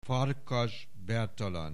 Aussprache Aussprache
FARKASBERTALAN.wav